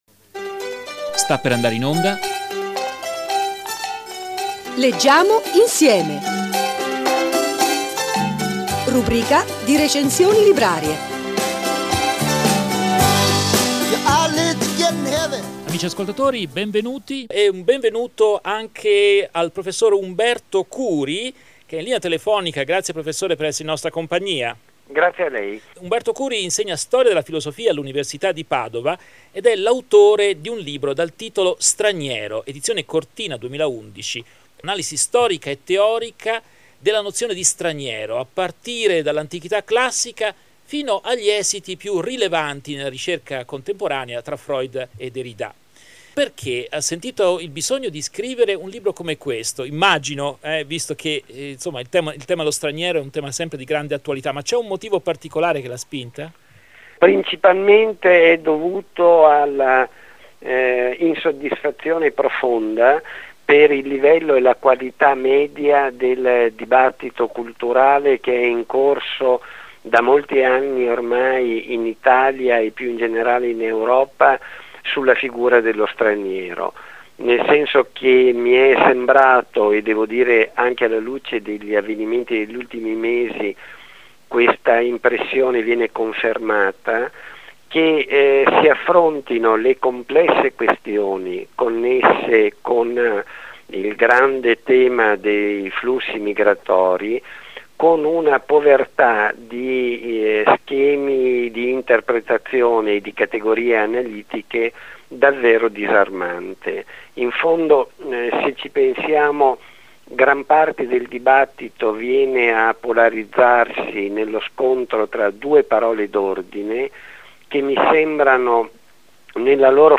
Intervista a Umberto Curi, docente di Stria della filosofia all’Universita’ di Padova e autore del saggio “Straniero”, ed. Cortina, 2011